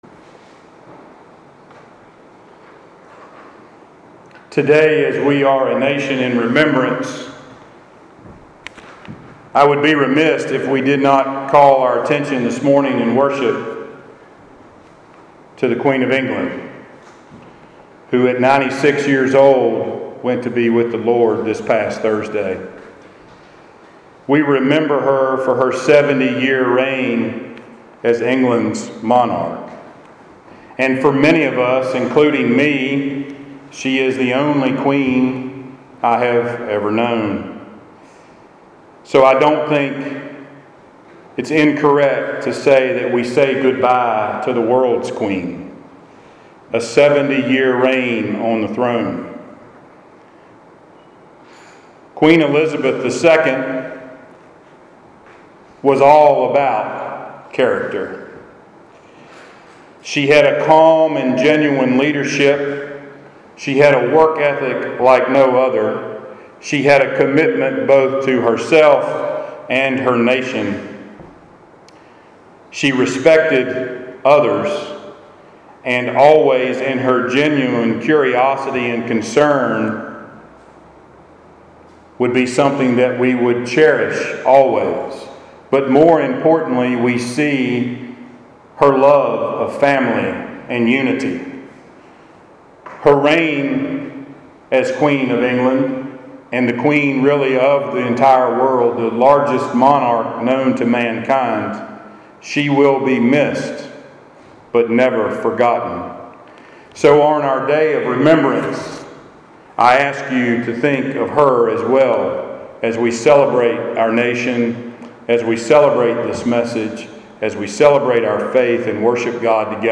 Passage: I Kings 11:1-13 Service Type: Sunday Worship